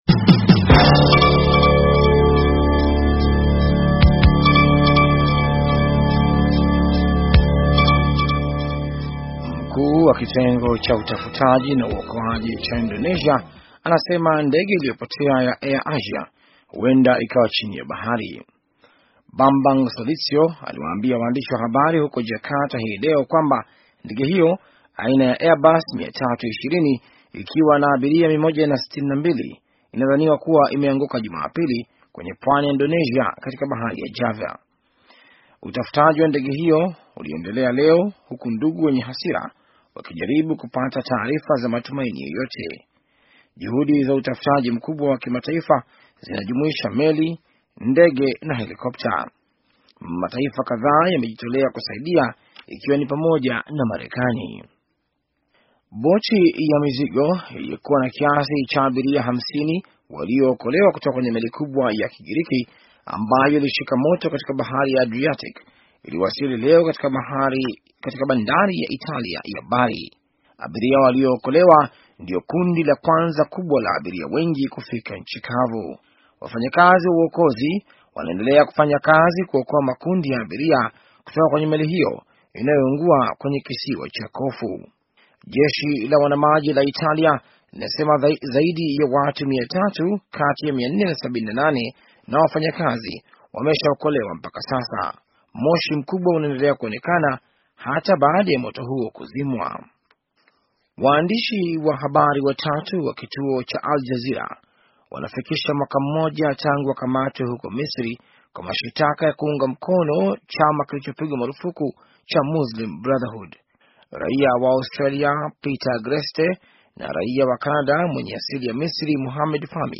Taarifa ya habari - 5:58